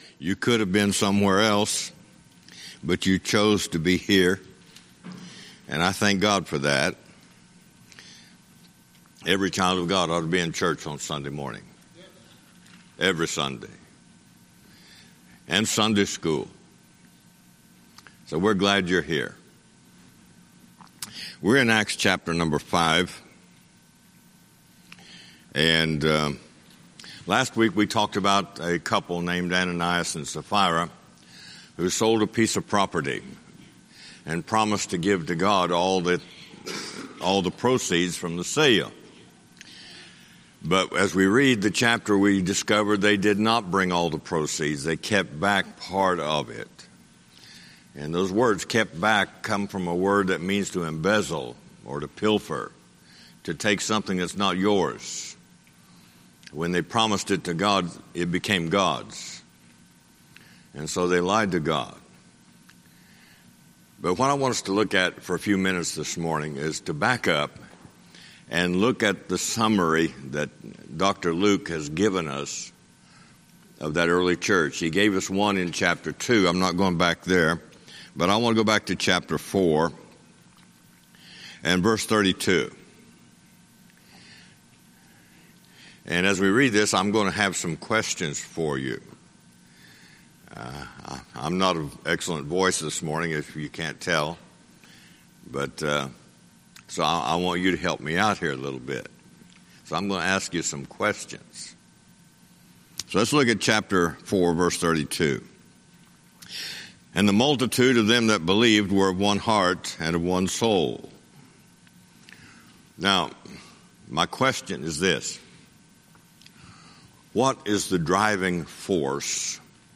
Sunday School Recordings
Guest Speaker